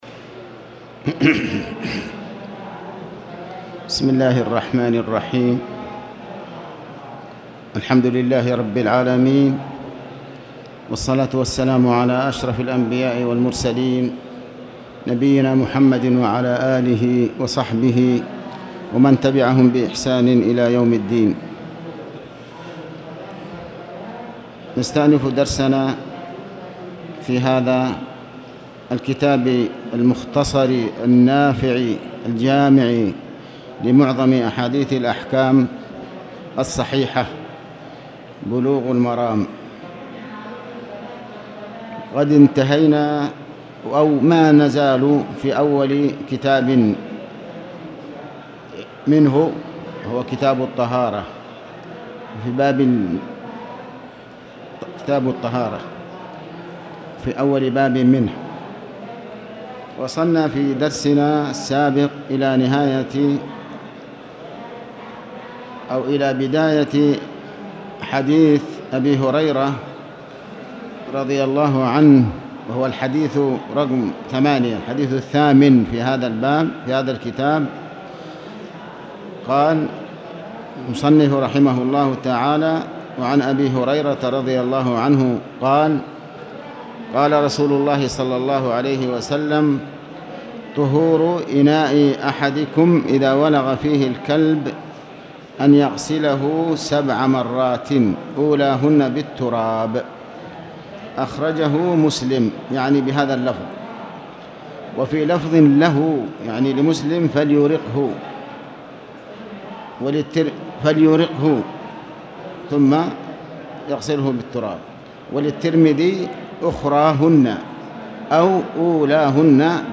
تاريخ النشر ٢٧ ذو الحجة ١٤٣٨ هـ المكان: المسجد الحرام الشيخ